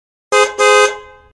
Dzwonki Klakson Samochodu Policyjnego
Kategorie Efekty Dźwiękowe